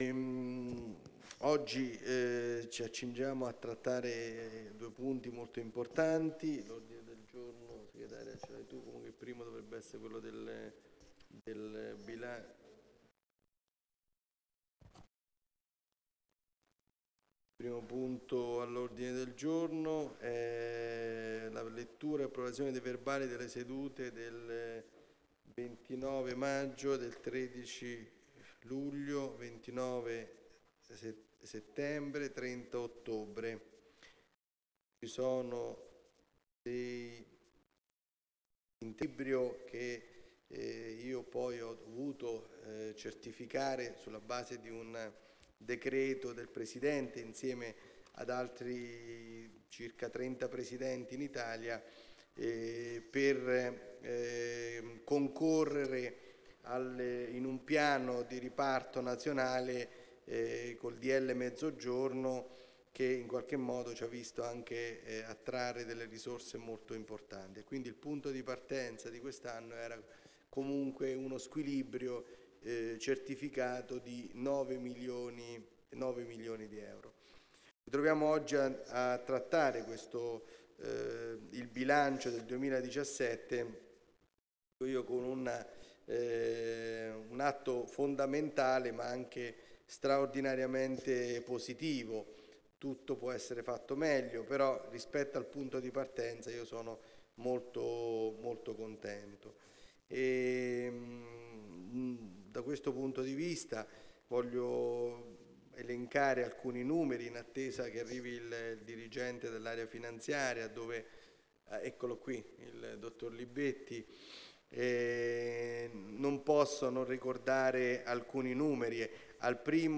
Seduta del consiglio del 21 Novembre 2017 - ore 15,30